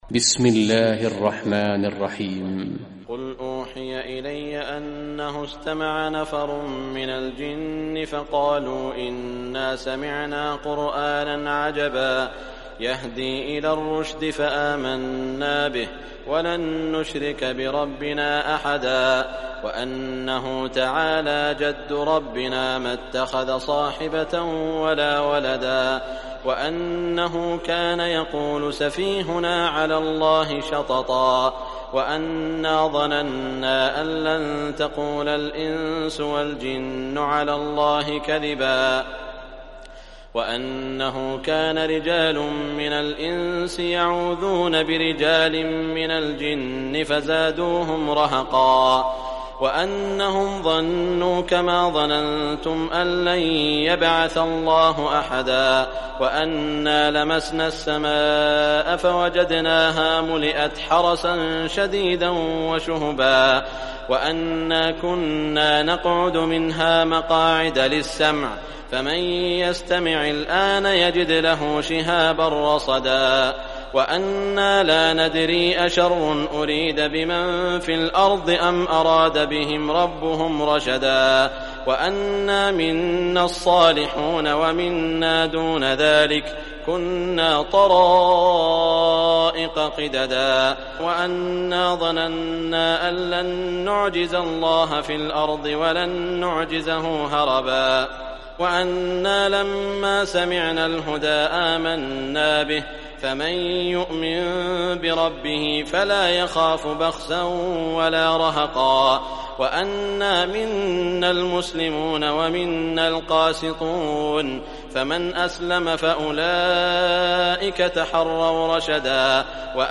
Surah Jinn Recitation by Sheikh Saud Shuraim
Surah Jinn, listen or play online mp3 tilawat / recitation in the beautiful voice of Sheikh Saud al Shuraim.